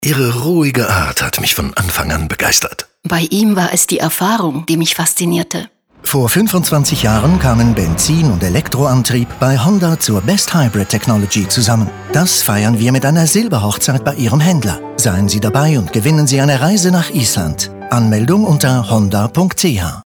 honda-hybrid-25ans-radio-de-final-preview.mp3